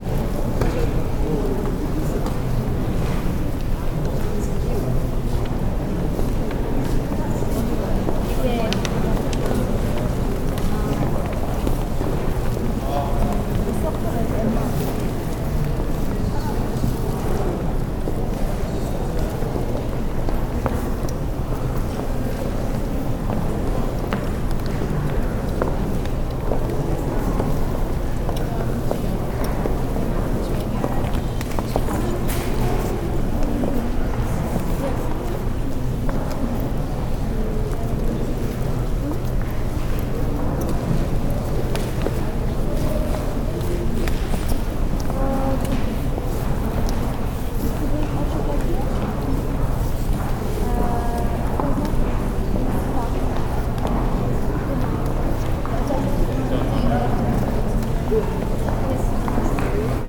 Здесь собраны атмосферные записи: от шороха шагов по паркету до приглушенных разговоров в выставочных залах.
Обстановка в Национальной Галерее Лондона: тихие голоса, люди двигаются вокруг